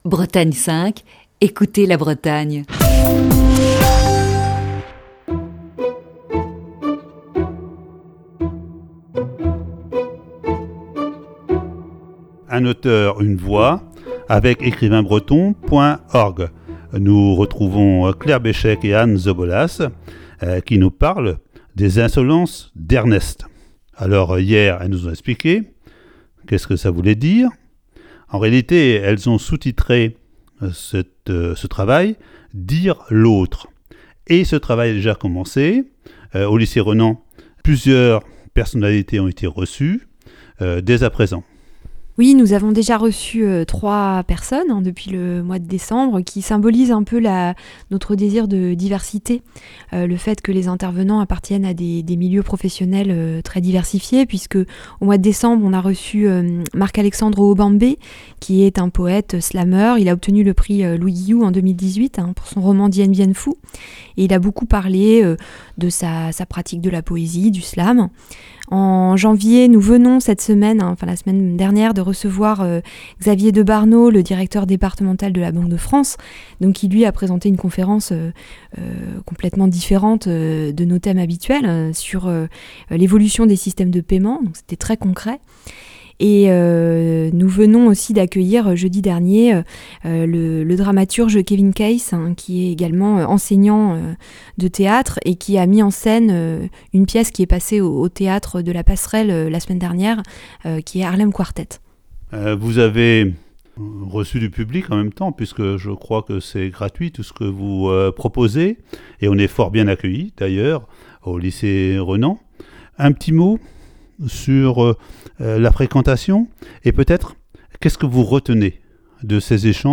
Aujourd'hui, deuxième partie de cet entretien.